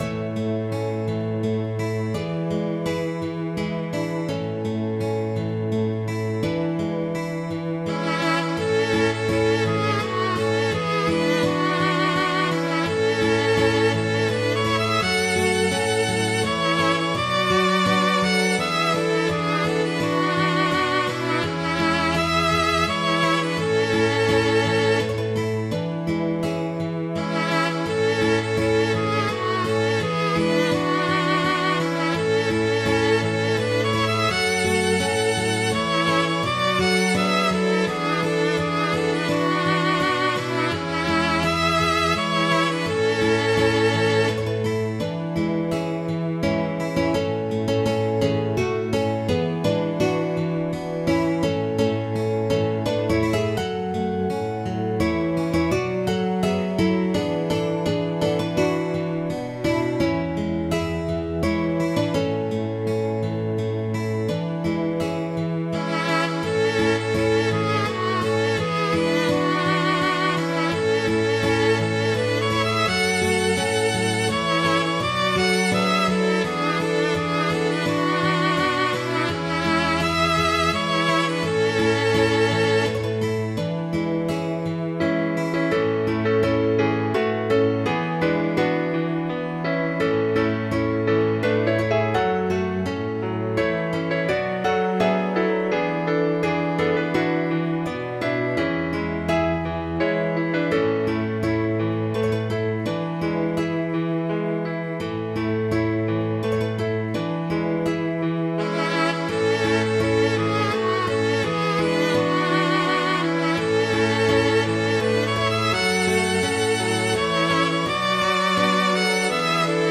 Midi File, Lyrics and Information to Lady Margaret